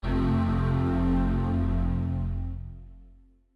AXEFIAudio_VoiceOver_Boot.wav